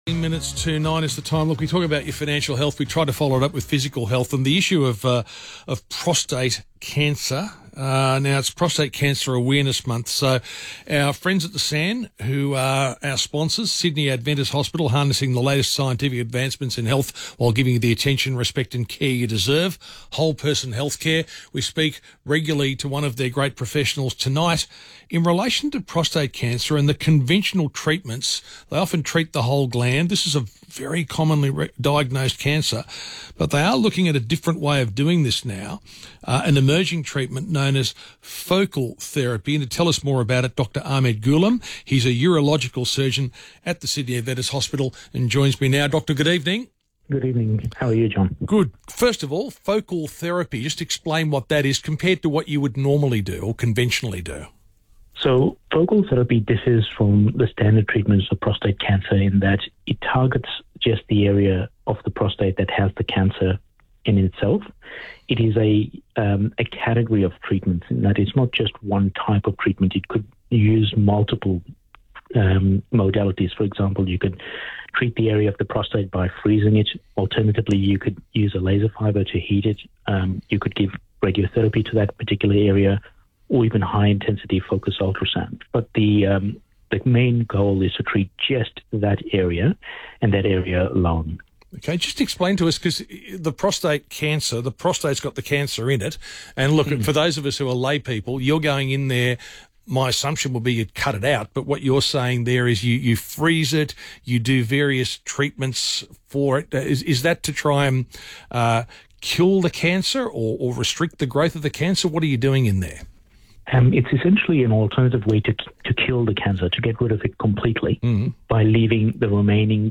Radio interviews & Mentors in Medicine Podcast
Urological surgeon